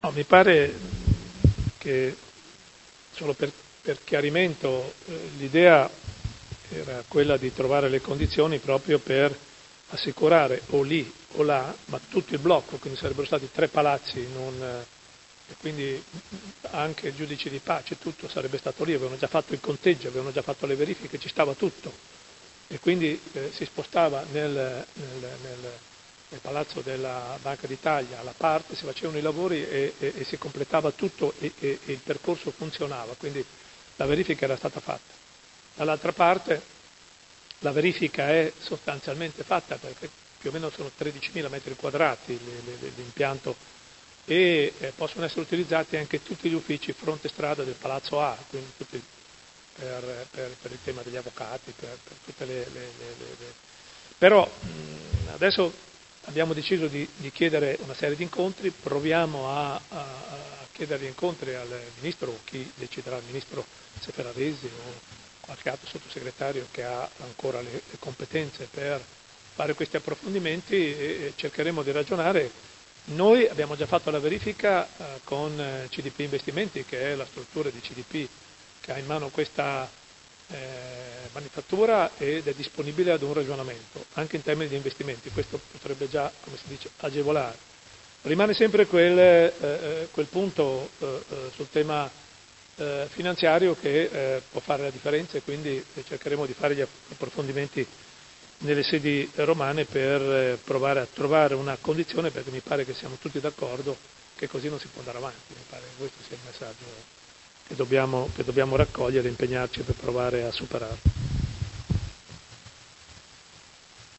Seduta del 17/10/2019 Replica a dibattito. Prot. Gen. n. 248897 Interrogazione dei Consiglieri Carpentieri e Carriero (PD) avente per oggetto: Cittadella della giustizia: aggiornamenti ed azioni concrete.